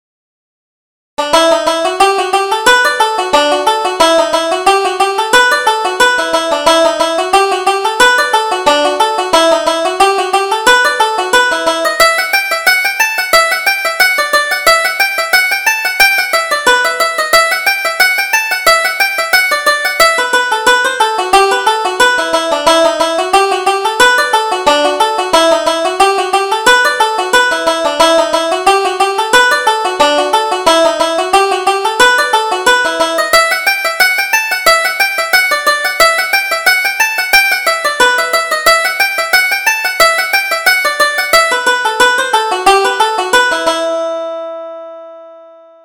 Reel: Considine's Grove